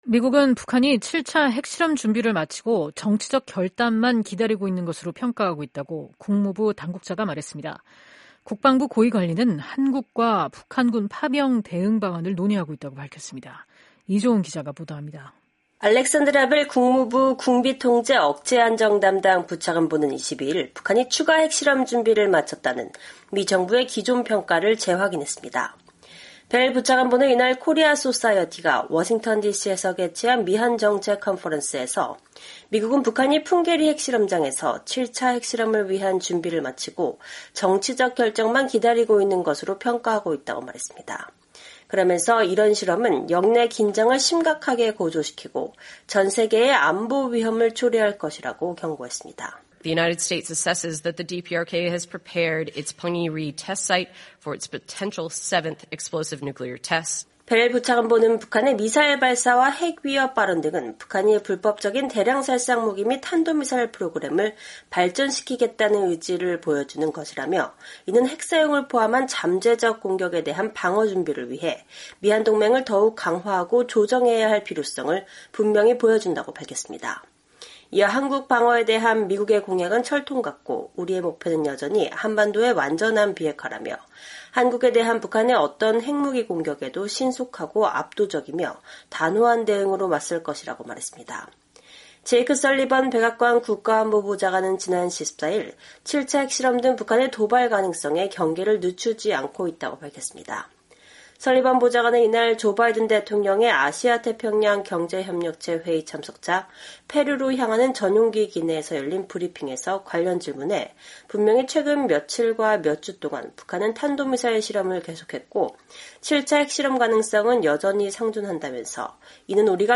벨 부차관보는 이날 코리아소사이어티가 워싱턴 DC에서 개최한 미한 정책 컨퍼런스에서 “미국은 북한이 풍계리 핵실험장에서 7차 핵실험을 위한 준비를 마치고 정치적 결정만 기다리고 있는 것으로 평가하고 있다”고 말했습니다.